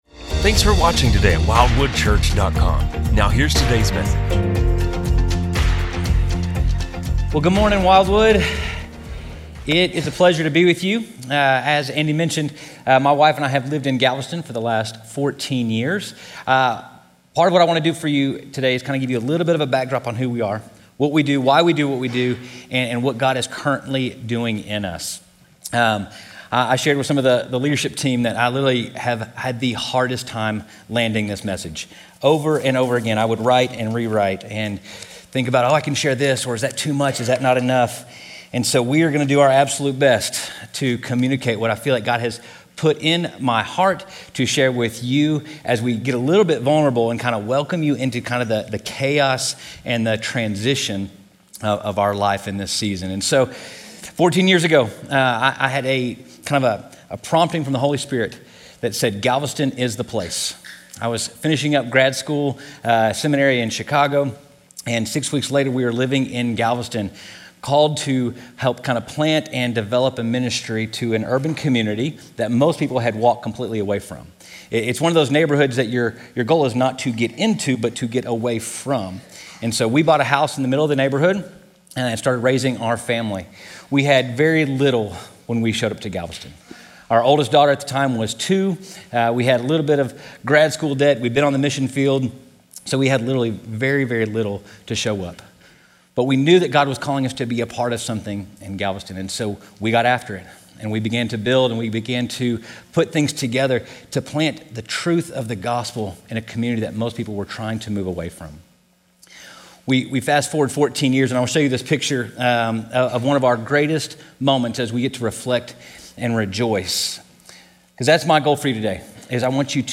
Sermons that are part of no other series.